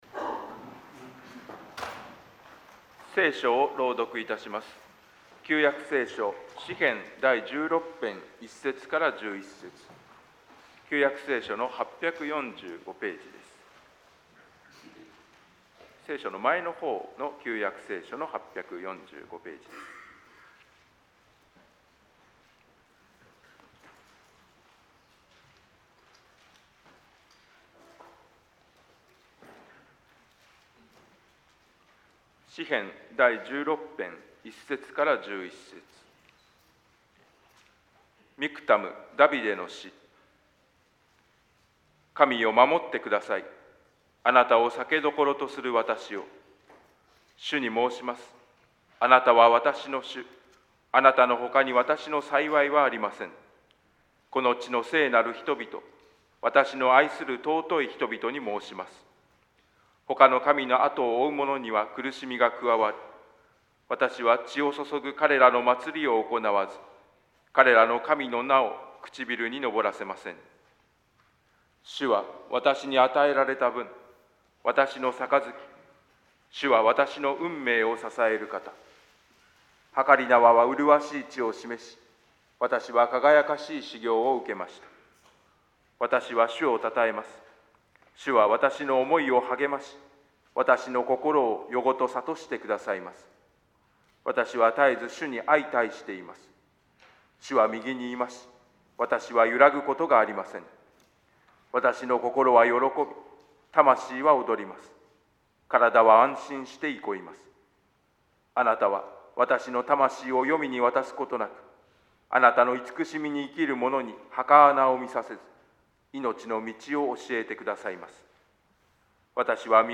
召天者記念礼拝 説教題